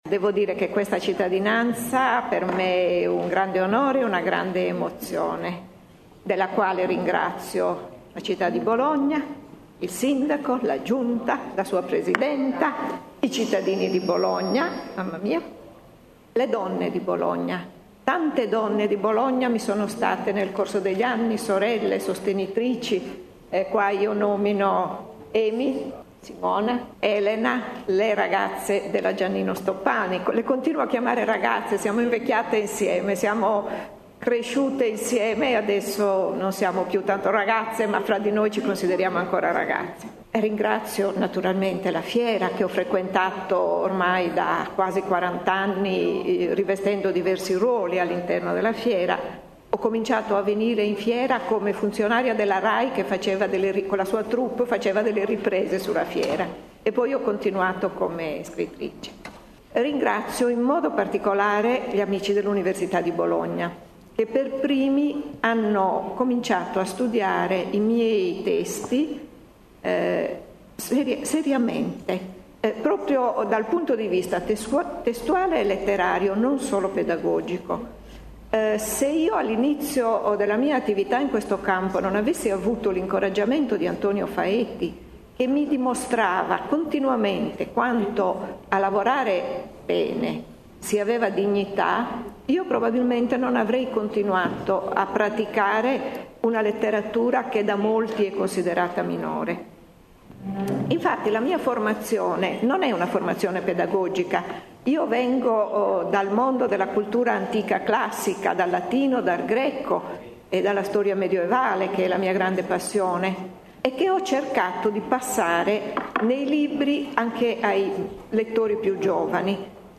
Ascolta il discorso di Bianca Pitzorno al Consiglio Comunale straordinario dove ha ringraziato “le ragazze di Bologna”.